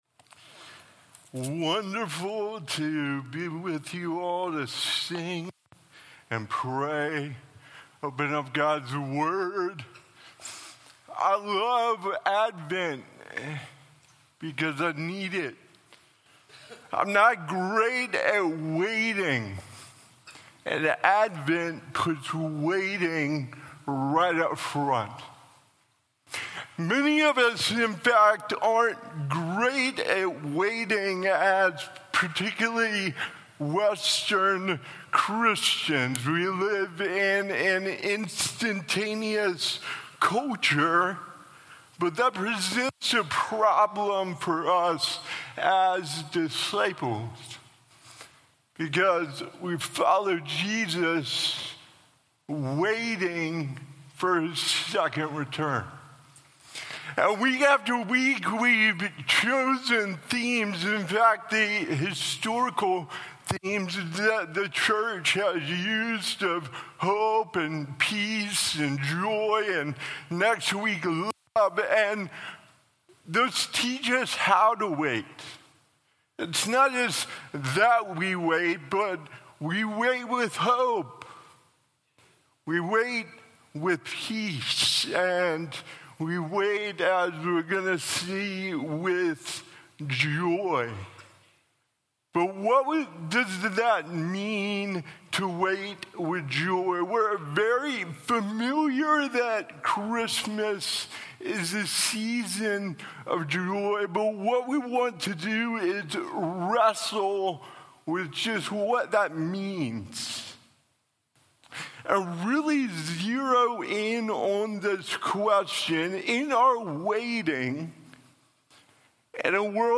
A message from the series "Advent 2023."